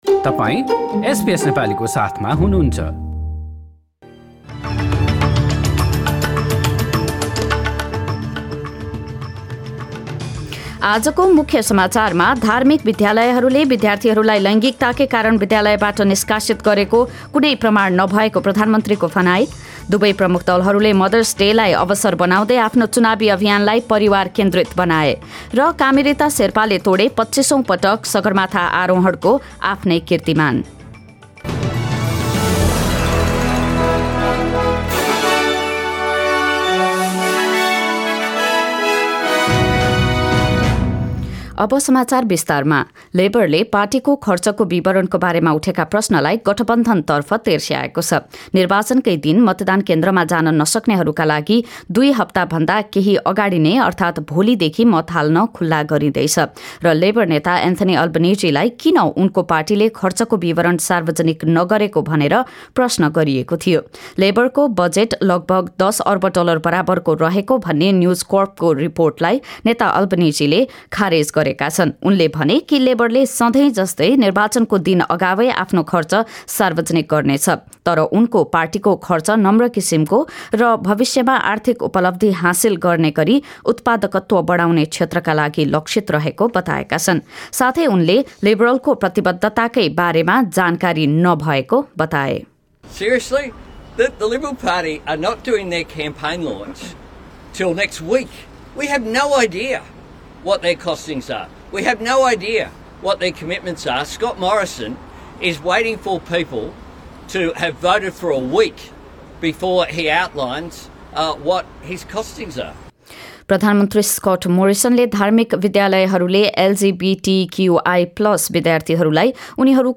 एसबीएस नेपाली अस्ट्रेलिया समाचार: आइतबार ८ मे २०२२